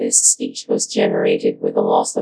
tts_output.wav